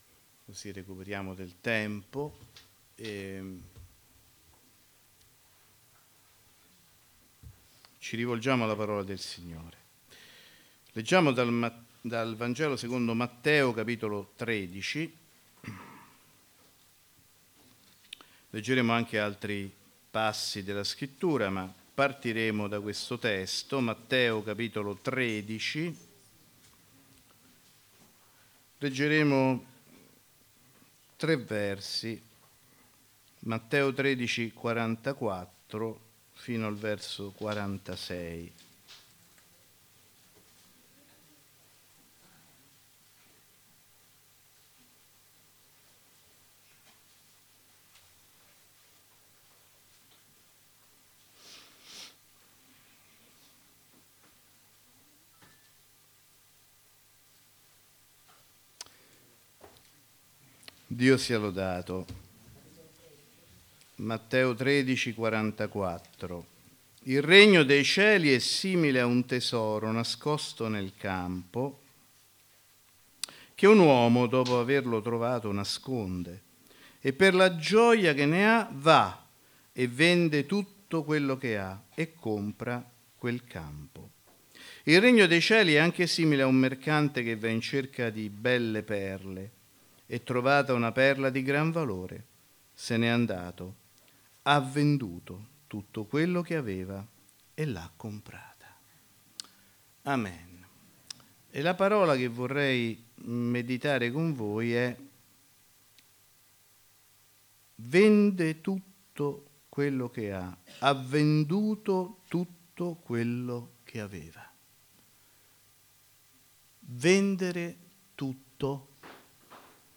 Predicatore